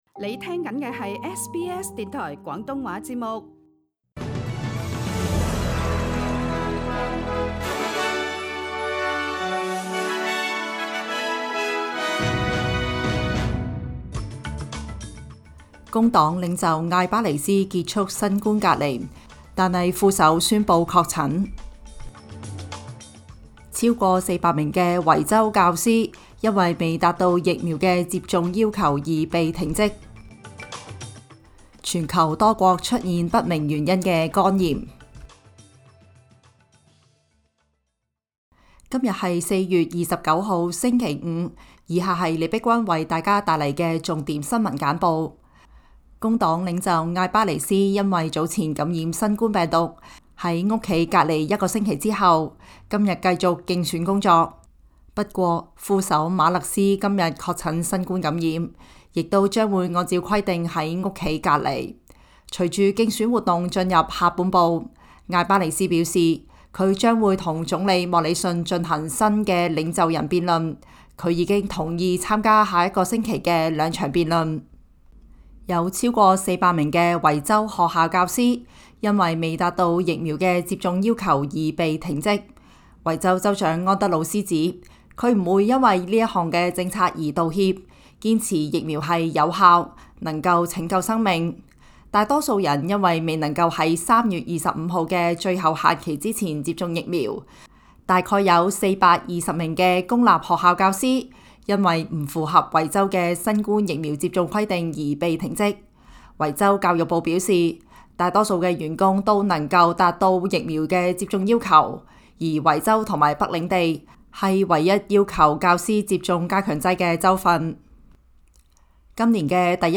SBS 新闻简报（4月29日）
SBS 廣東話節目新聞簡報 Source: SBS Cantonese